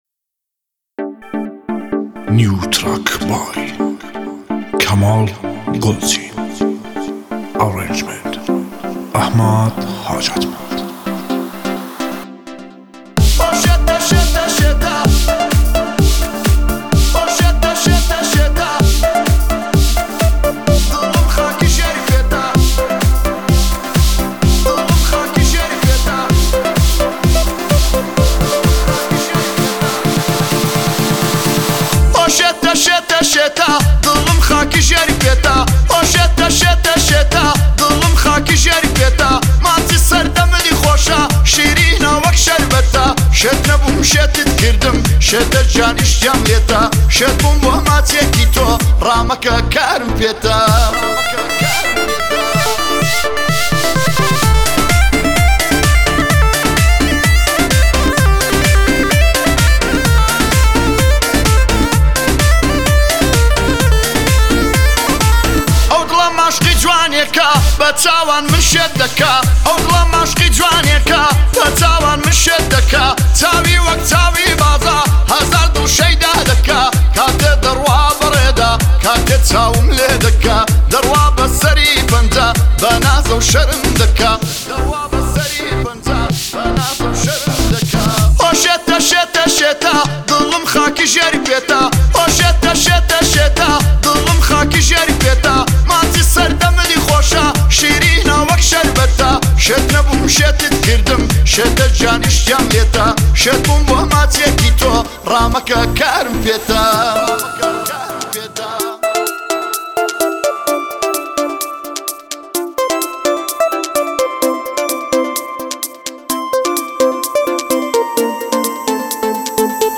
آهنگ کردی